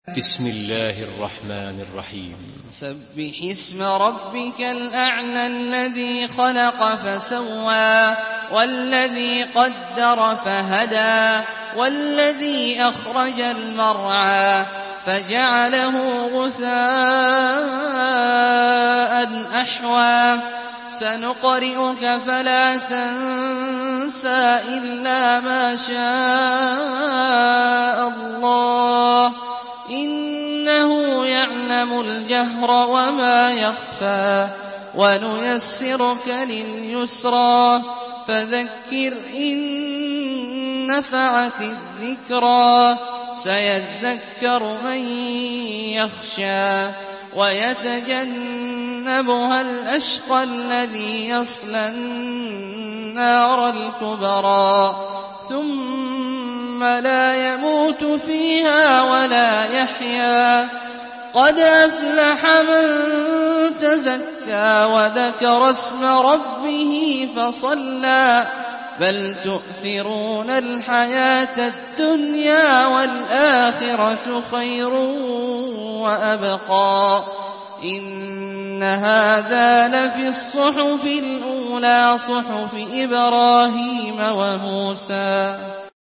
Surah Ala MP3 by Abdullah Al-Juhani in Hafs An Asim narration.
Murattal Hafs An Asim